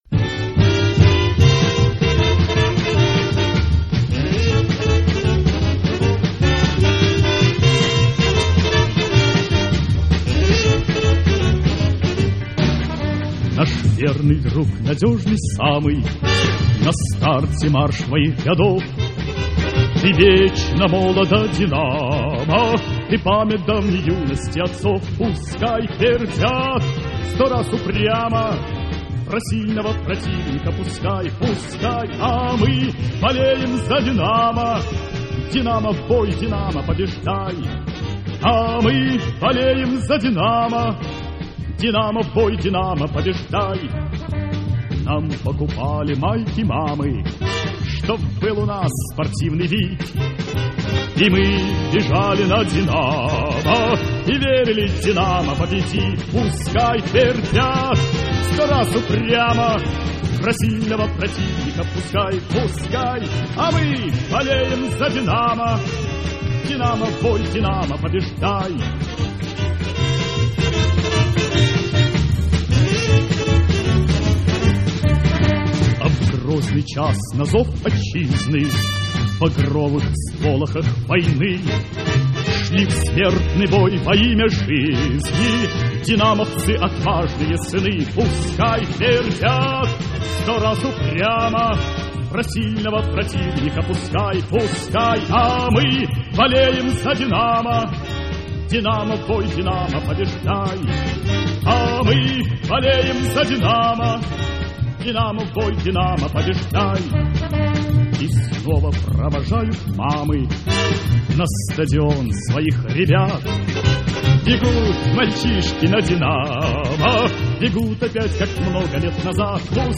Полу-"попса".